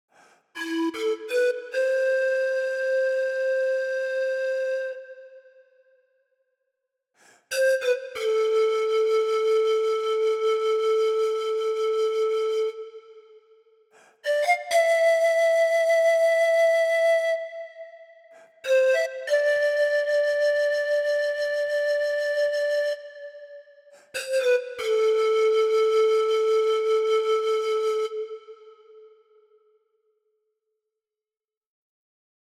Chromatic Pan Flute features a large Peruvian pan flute, ready to play right away.
Listen to – Mixed Dynamic Layers